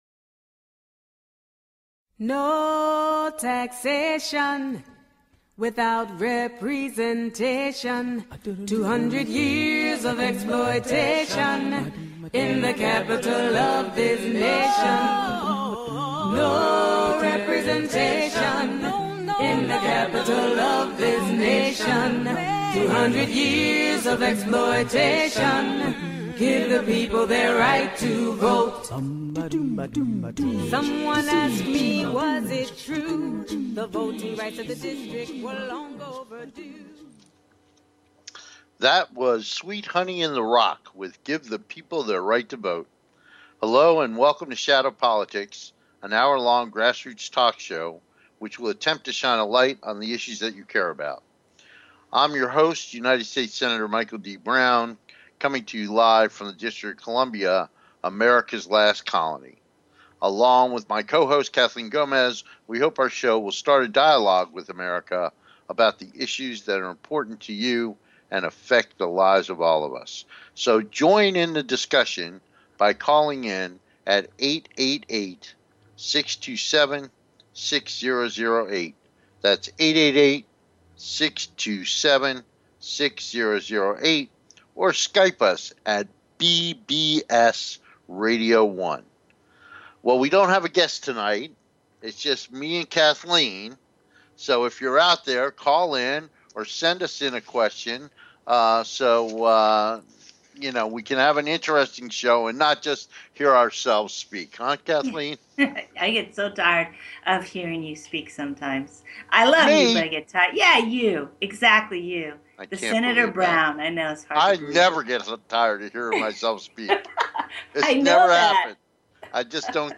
Shadow Politics is a grass roots talk show giving a voice to the voiceless. For more than 200 years the people of the Nation's Capital have ironically been excluded from the national political conversation.
We look forward to having you be part of the discussion so call in and join the conversation.